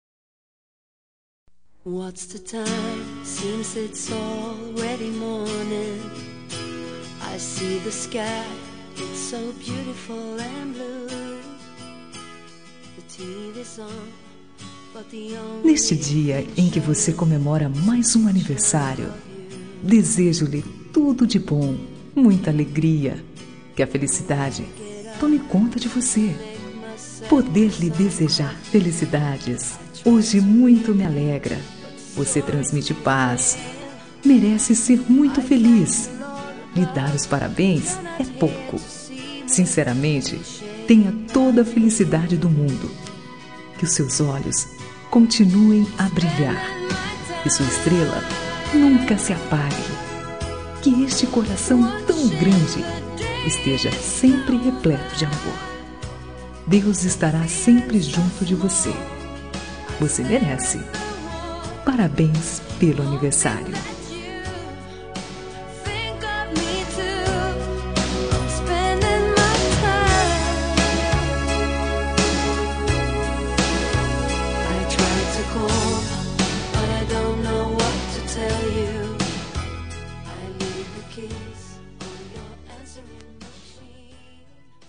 Aniversário de Pessoa Especial – Voz Feminina – Cód: 1883